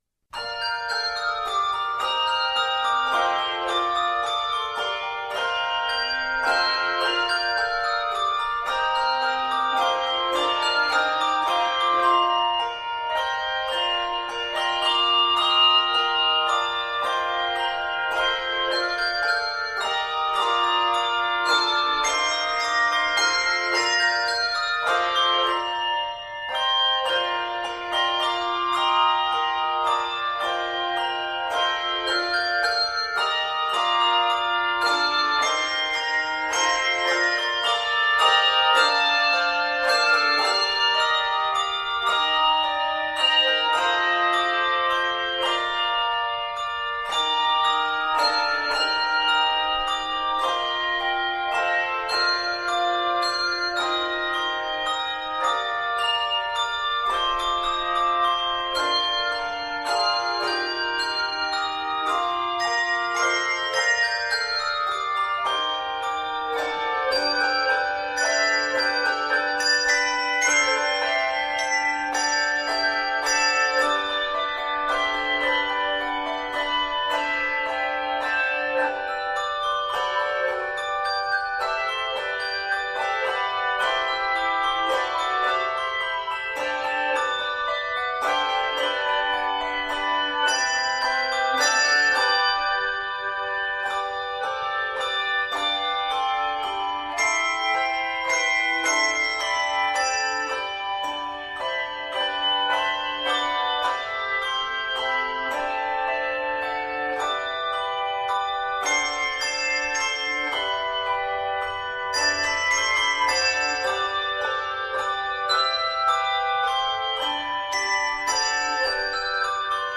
two- and three-octave handbell arrangements